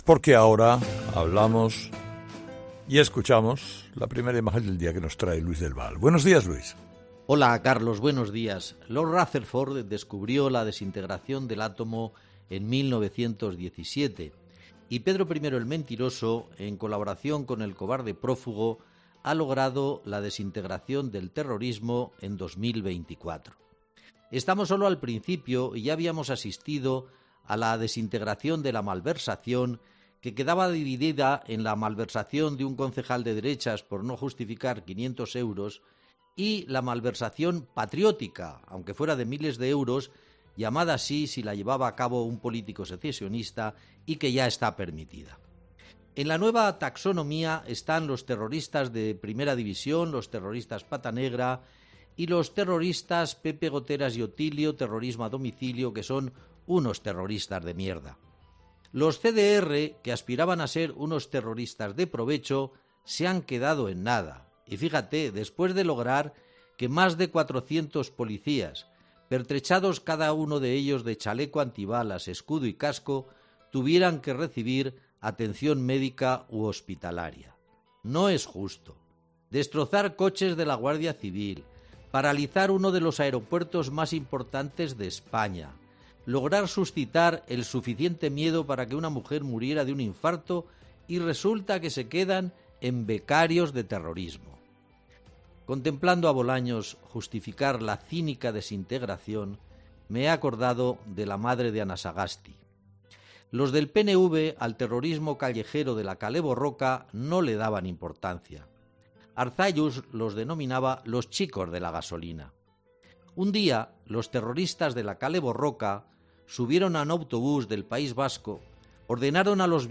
Luis del Val pone el foco de la imagen del día de "Herrera en COPE" en el pacto que el PSOE ha cerrado con Junts y ERC de incluir en la amnistía los delitos de terrorismo sin “violaciones graves de derechos humanos":